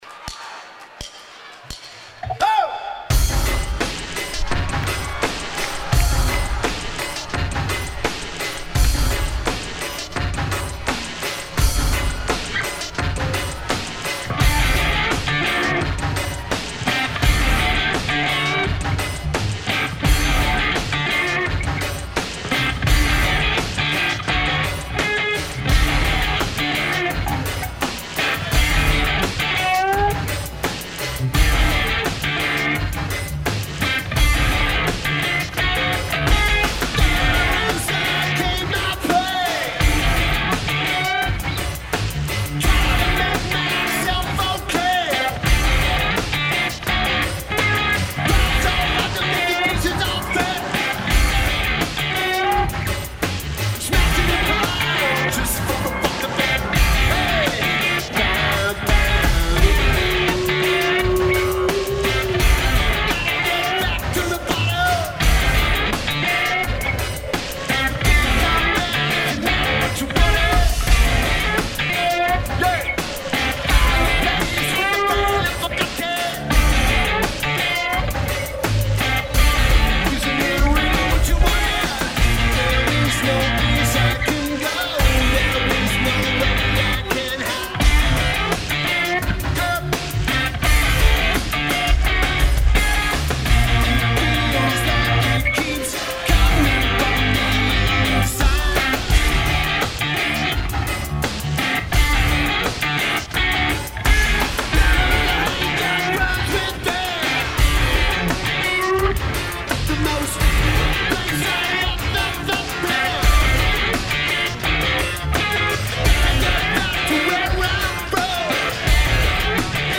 Fox Theatre